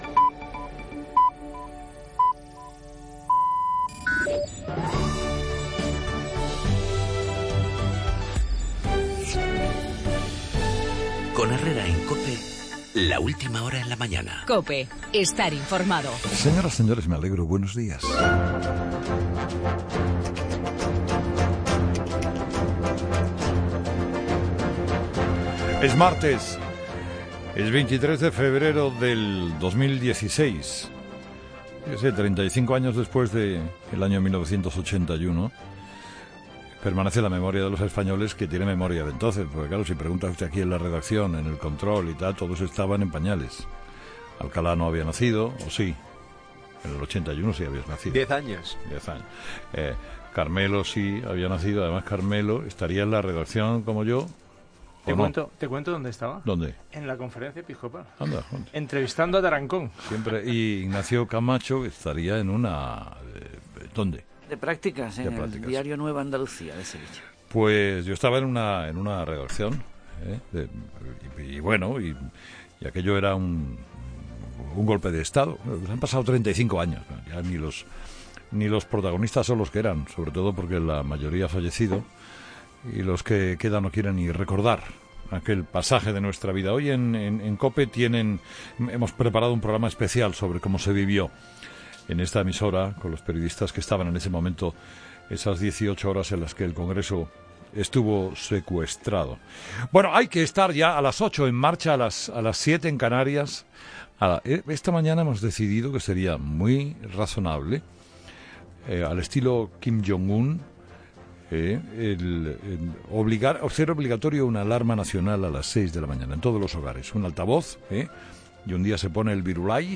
El 35 aniversario del 23-F y el Golpe de Estado; las cuentas que no salen para la investidura de Pedro Sánchez tras las negociaciones a dos bandas mantenidas este lunes; y la decisión del Ayuntamiento de Pontevedra de nombrar personas 'nono grata' a Mariano Rajoy, en el editorial de Carlos Herrera a las 8 de la mañana.